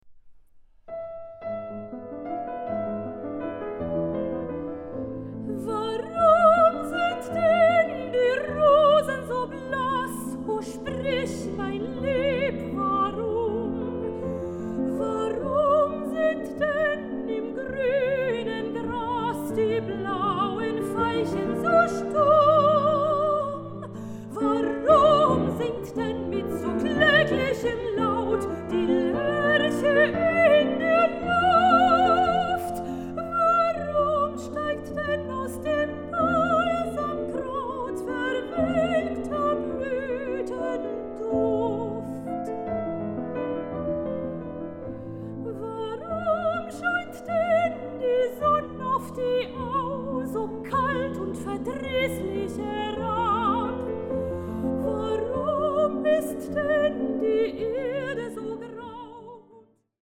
Songs of Flowers and Trees
Recording: Alfried-Krupp-Saal, Philharmonie Essen, 2024